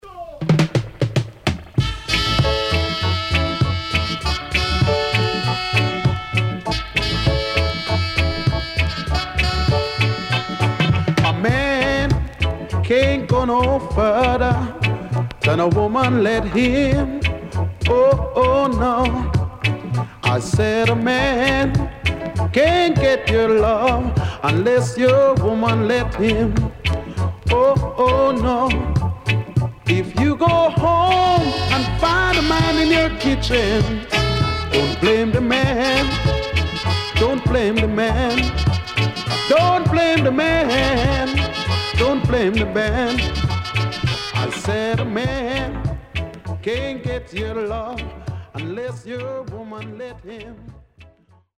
Funky Reggae Vocal
SIDE A:少しノイズ入りますが良好です。